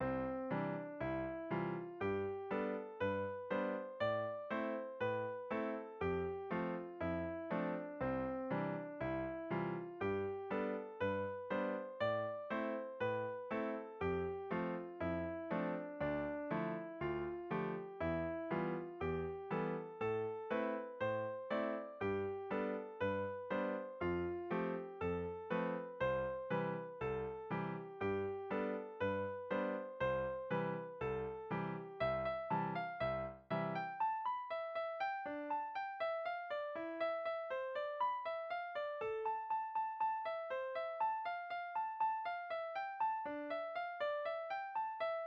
→ 그대로 여전히 단조로와요…
• 결국 여러번 수정작업을 하였으나 기본멜로디와 악보만 만들어졌습니다.